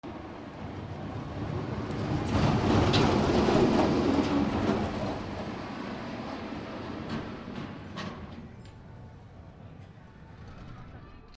ミニコースター通過
遊園地MKH416